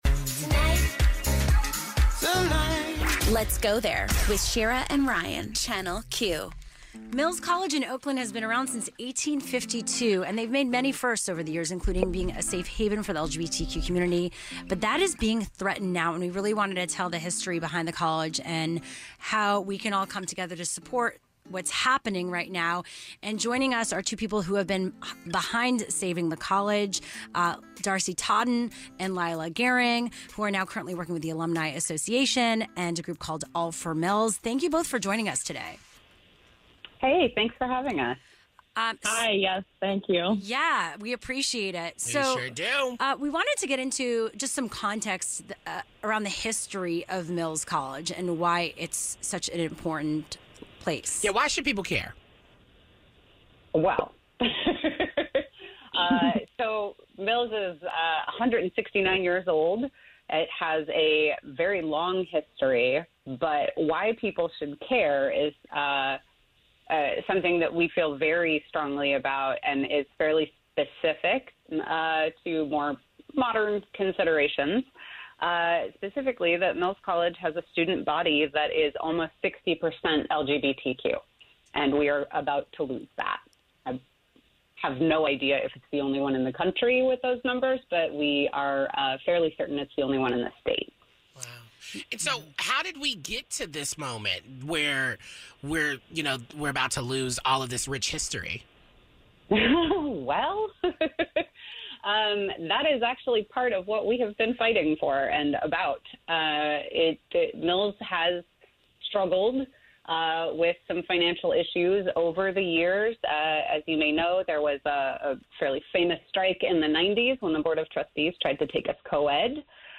Mills students are in the midst of transferring. Hear from a recent transfer who is now at Susan Mills' alma mater, Mt. Holyoke